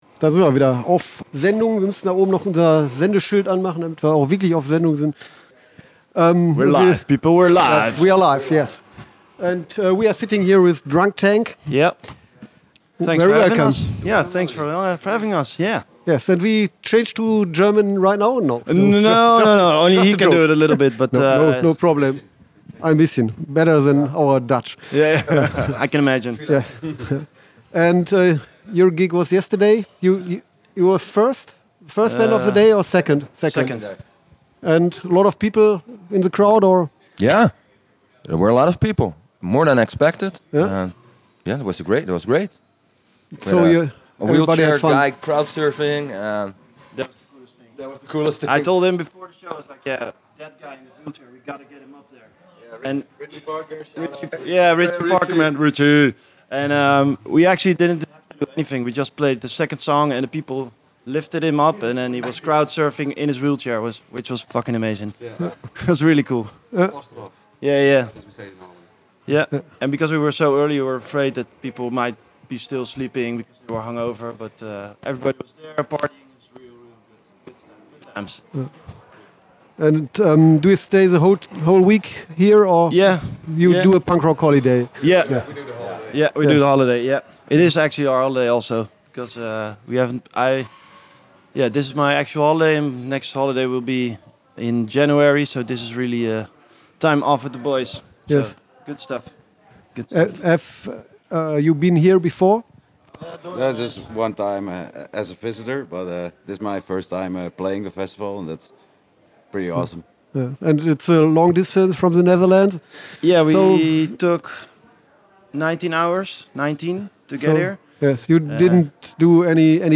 Letzte Episode Drunktank – Interview @ Punk Rock Holiday 1.9 20. August 2019 Nächste Episode download Beschreibung Teilen Abonnieren Drunktank from the Netherlands visited us at our little festival radio studio for a short interview.
drunktank-interview-punk-rock-holiday-1-9-mmp.mp3